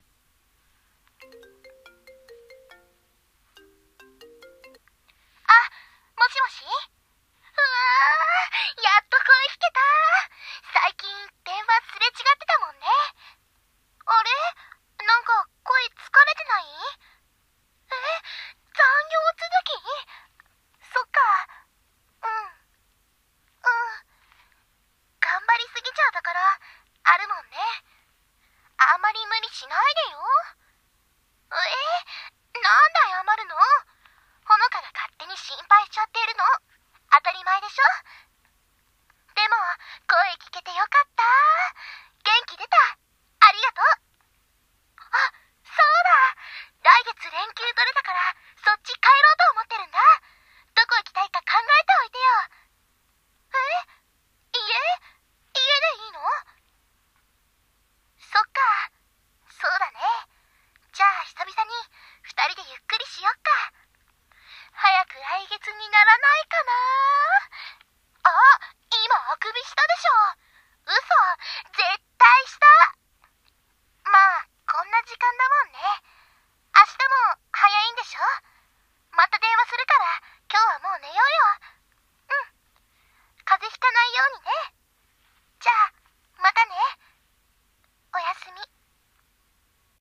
【電話声劇】遠距離中の恋人へ